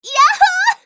archer_f_voc_social_win_b.mp3